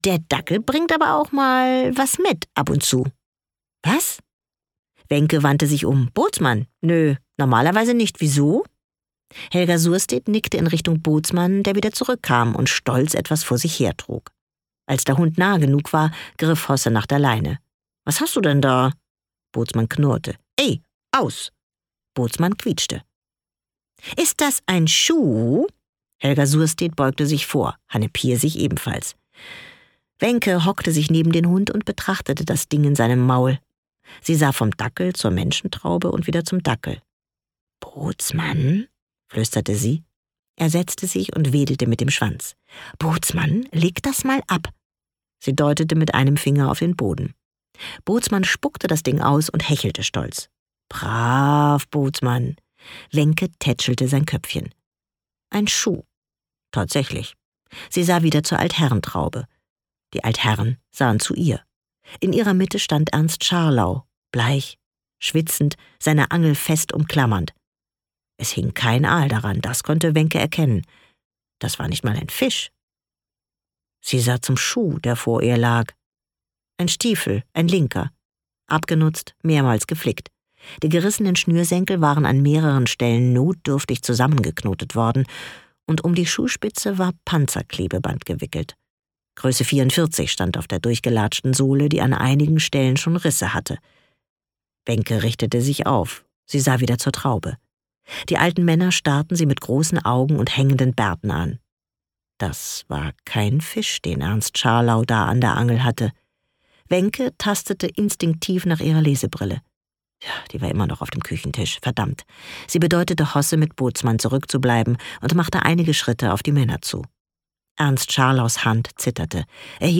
Ein norddeutscher Krimi
Gekürzt Autorisierte, d.h. von Autor:innen und / oder Verlagen freigegebene, bearbeitete Fassung.